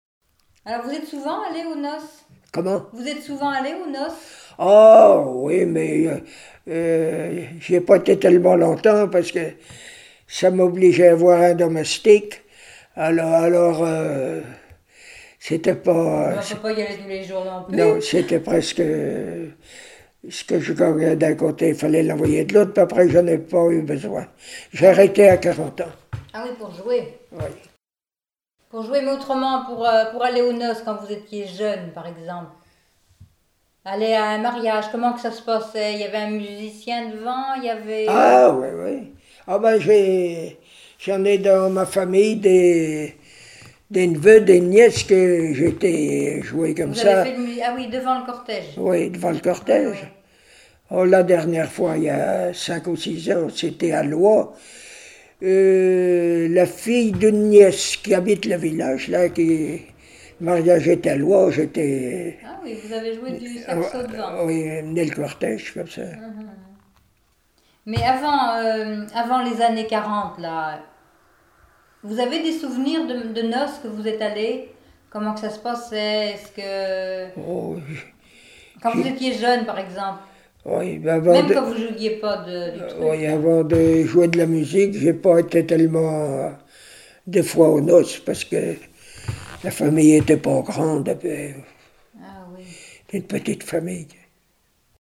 Enquête Arexcpo en Vendée-Pays Sud-Vendée
Catégorie Témoignage